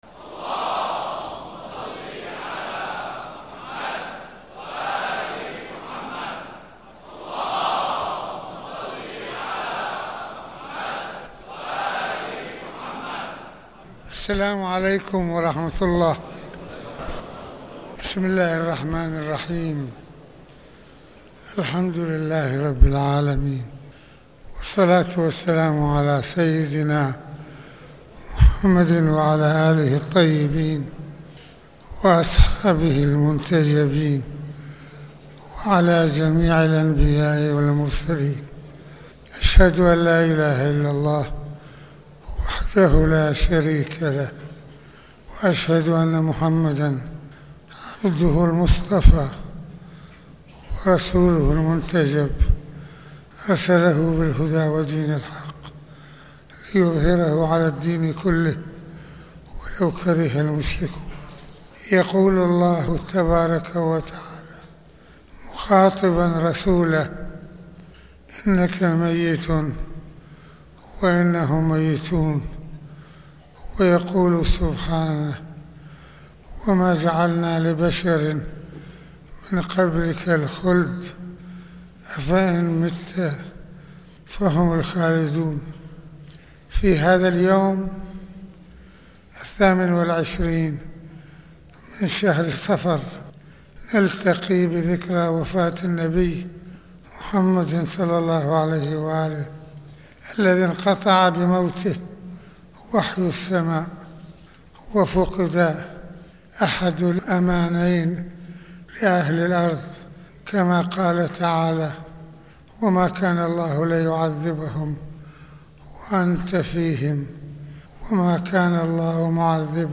- المناسبة : خطبة الجمعة
المكان : مسجد الإمامين الحسنين (ع)